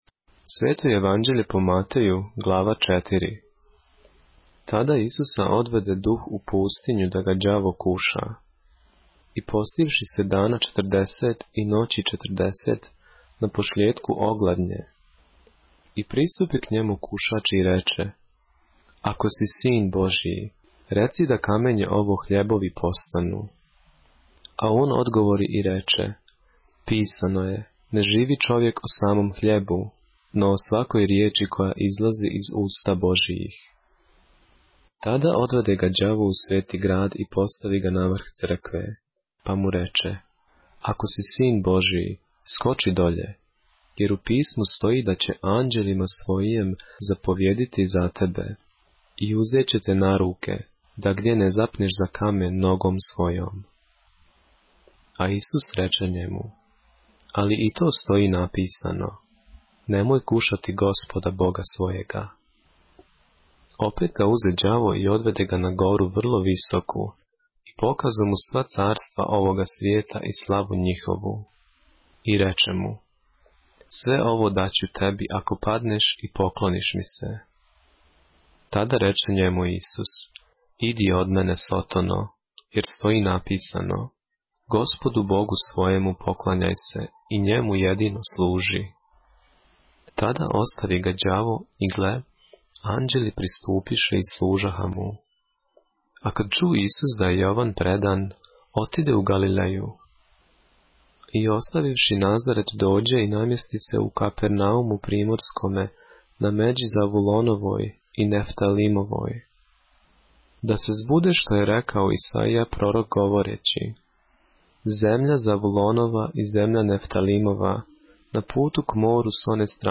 поглавље српске Библије - са аудио нарације - Matthew, chapter 4 of the Holy Bible in the Serbian language